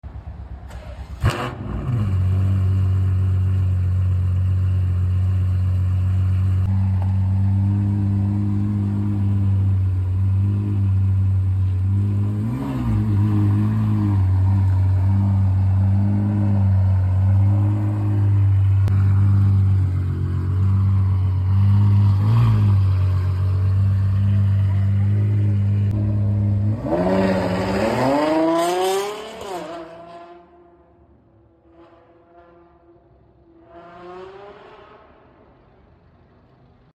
Jedno z najgłośniejszych aut jakie w życiu było dane mi usłyszeć…